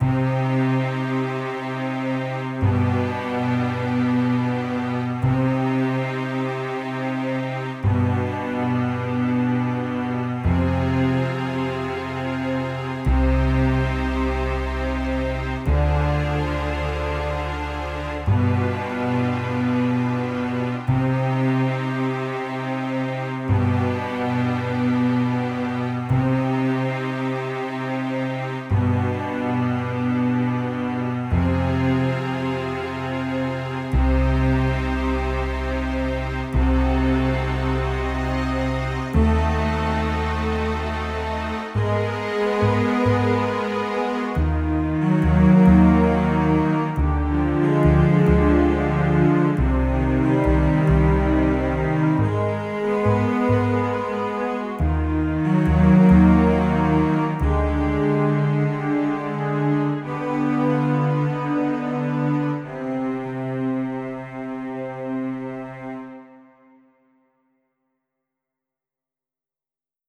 Scene Change, Theater Music